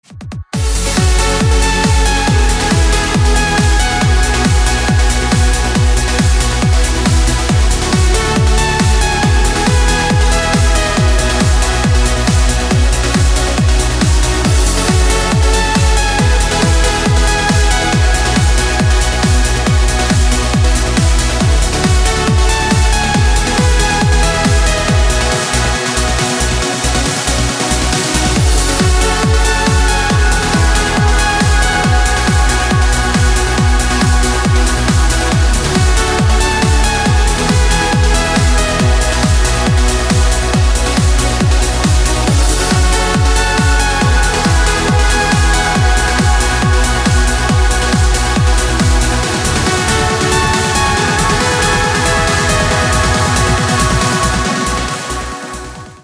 • Качество: 256, Stereo
громкие
dance
Electronic
электронная музыка
без слов
club
Trance